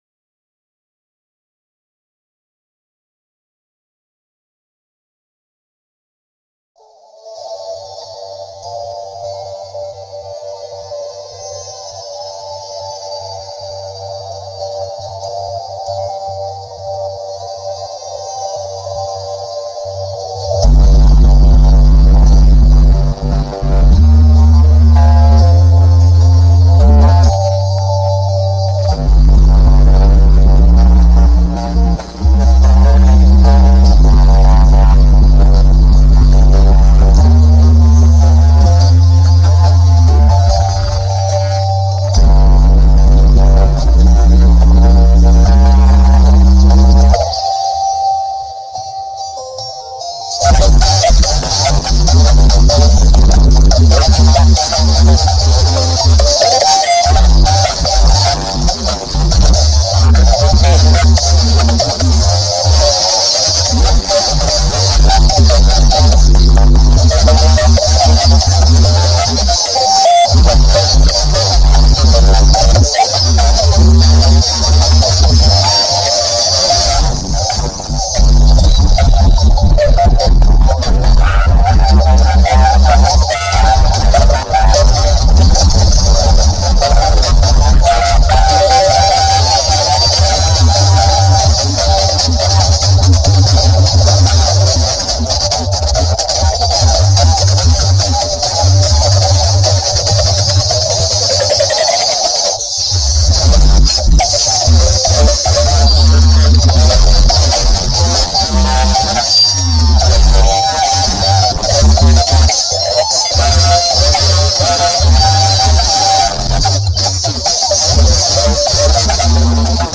in pitch +12